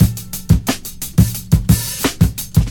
117 Bpm Breakbeat Sample A# Key.wav
Free drum groove - kick tuned to the A# note. Loudest frequency: 1926Hz
117-bpm-breakbeat-sample-a-sharp-key-IjU.ogg